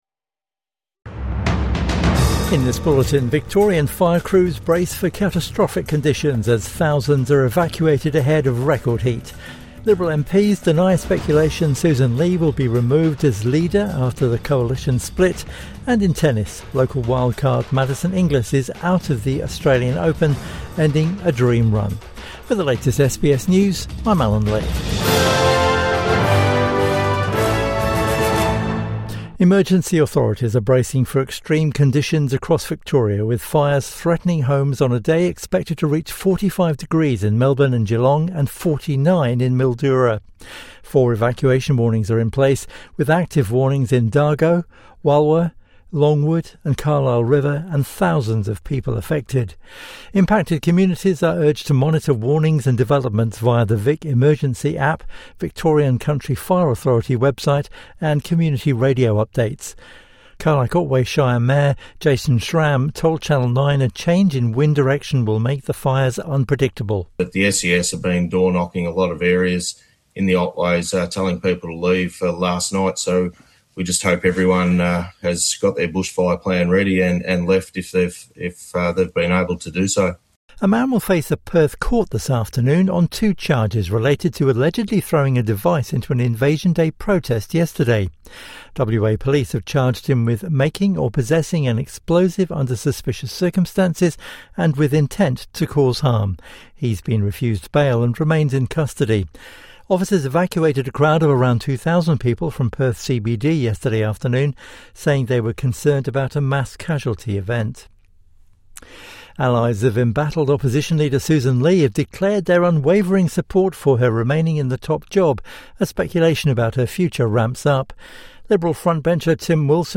Heat records tumble in Victoria | Midday News Bulletin 27 January 2026